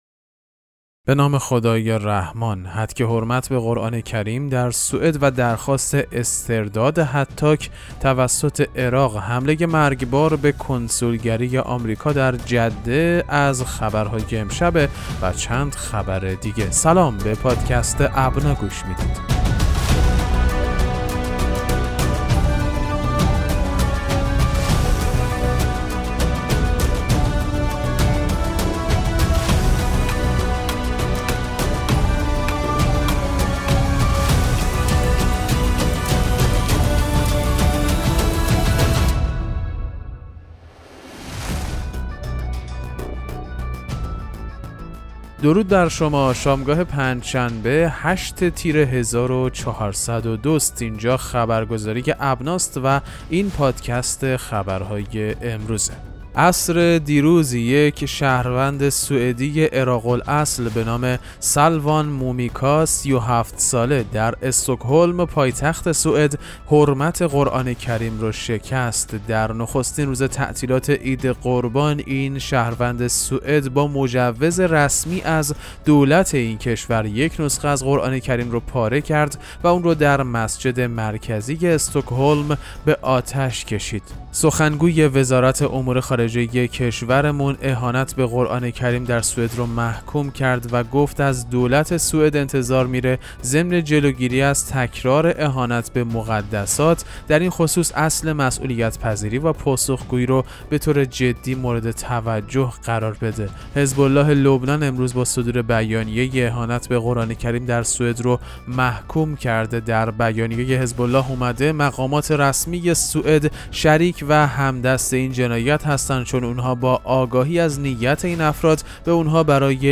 پادکست مهم‌ترین اخبار ابنا فارسی ــ 8 تیر 1402